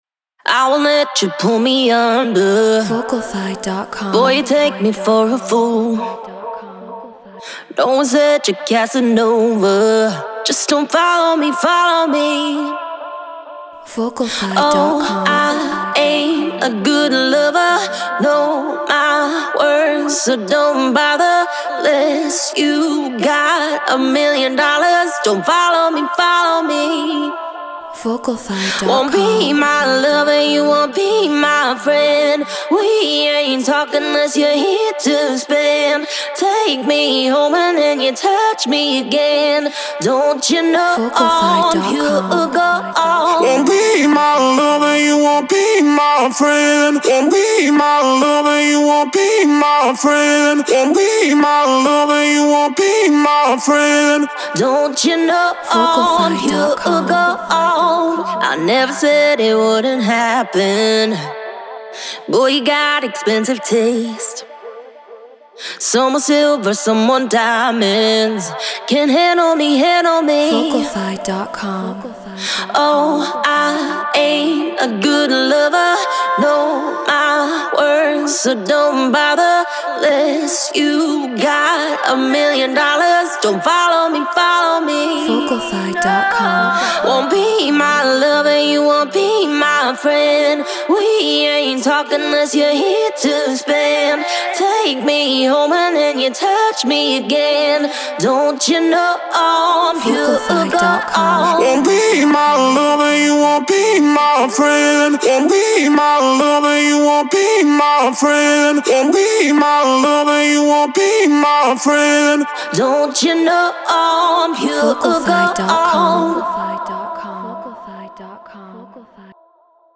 House 132 BPM Dmin